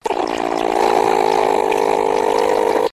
звуки животных